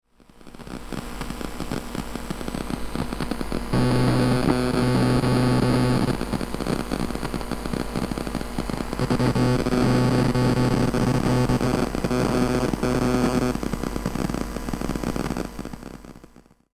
Geräusche über das Monitoring, wenn kein Audio abgespielt wird
Ich habe sie mit meinem Field-Recorder gemacht, welchen ich vor einem Monitor gehalten habe. Ihr hört Störgeräusche, die ständig zu hören sind, 2x bewege ich die Maus. Ich meine, das es Störgeräusche vom PC sind - nur was in der Kette fängt diese ein? Die Audio-Datei gibt es natürlich lauter wieder, ich höre es bei mir leiser aber doch nervend, wenn keine Mucke abgespielt wird.